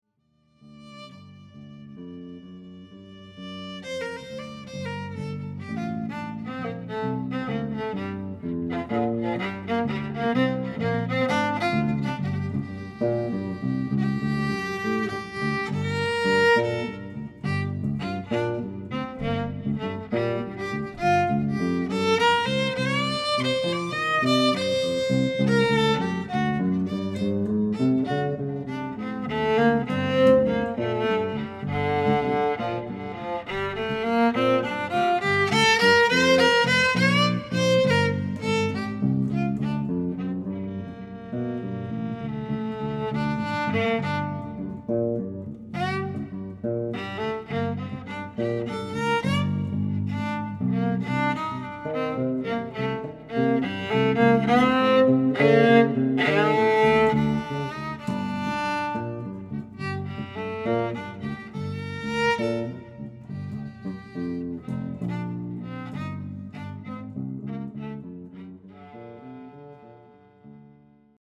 violin, accordion, viola, guitar, & vocals
French horn, electric bass